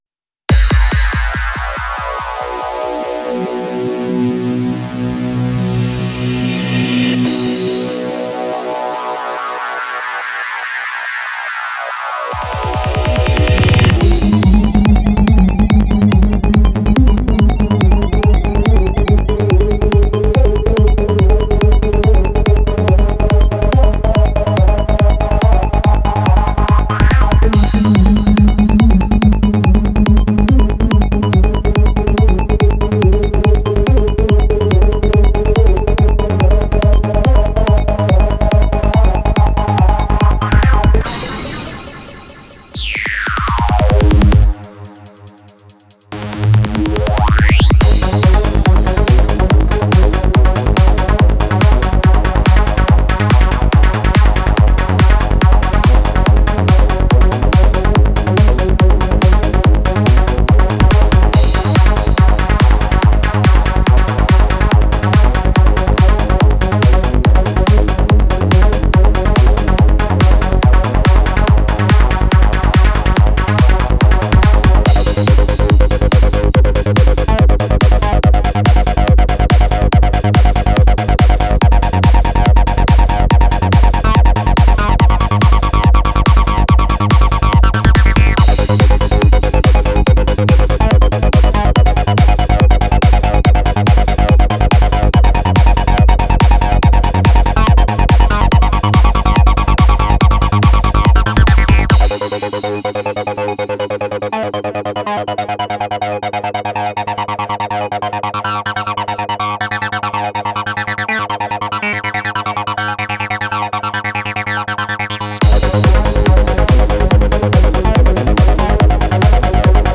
An impressive collection of Psytrance sounds.